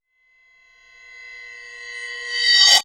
time_warp_reverse_high_05.wav